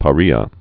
(pä-rēä), Gulf of